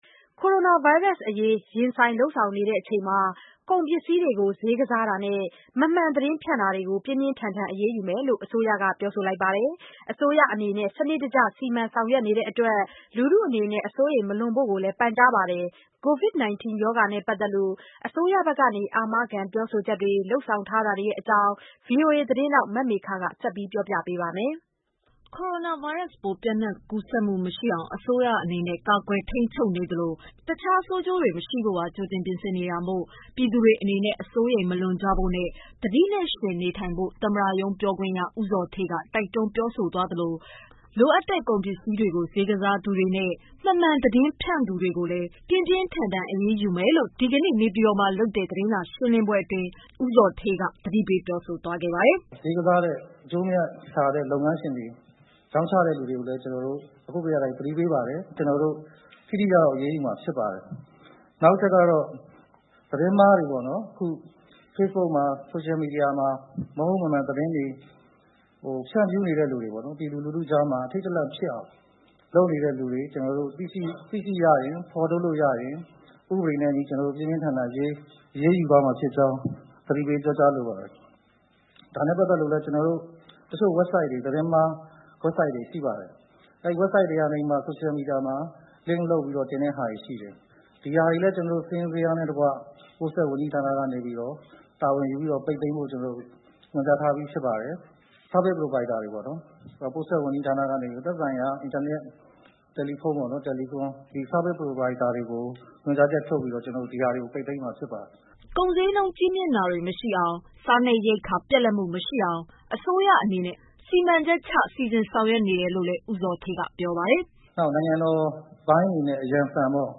ကိုရိုနာဗိုင်းရပ်စ်ပိုး ပျံ့နှံ့ကူးစက်မှု မရှိအောင် အစိုးရအနေနဲ့ ကာကွယ်ထိန်းချုပ်နေသလို တခြား ဆိုးကျိုးတွေ မရှိဖို့ပါ။ ကြိုတင် ပြင်ဆင်နေတာမို့ ပြည်သူတွေ အနေနဲ့ အစိုးရိမ် မလွန်ကြဖို့နဲ့ သတိနဲ့ ယှဉ်နေထိုင်ဖို့ သမ္မတရုံးပြောခွင့်ရ ဦးဇော်ဌေးက တိုက် တွန်းပြောဆိုသလို လိုအပ်တဲ့ ကုန်ပစည်းတွေကိုဈေးကစားသူတွေနဲ့ မမှန်သတင်းဖြန့် သူတွေကိုလည်း ပြင်းပြင်းထန်ထန် အရေးယူမယ်လို့ ဒီကနေ့ နေပြည်တော်မှာလုပ်တဲ့ သတင်းစာရှင်းလင်းပွဲအတွင်း သမ္မတရုံး ပြောခွင့်ရ ဦးဇော်ဌေးက သတိပေးသွားပါတယ်။